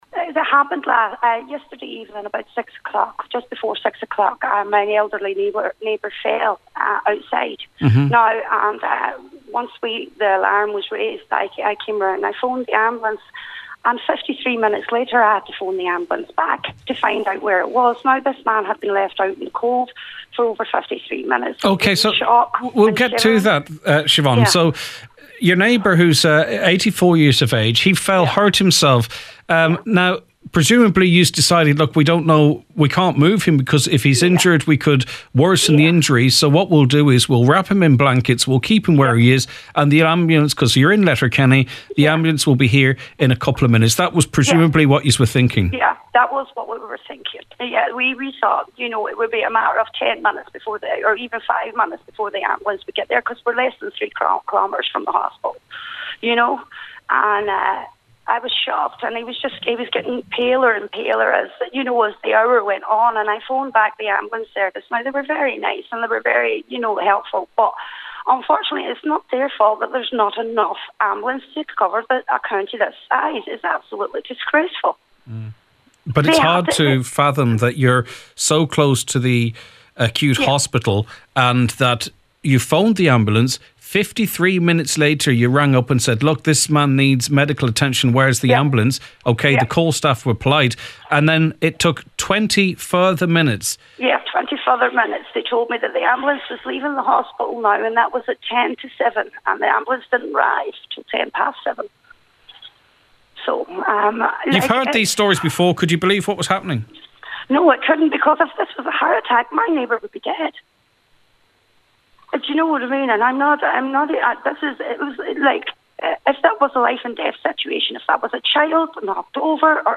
Speaking on the Nine Till Noon Show today